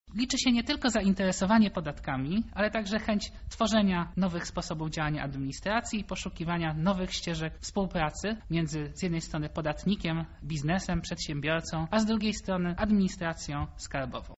Kim powinien być podatkowy lider? – na to pytanie odpowiada podsekretarz stanu w ministerstwie finansów  Jan Sarnowski: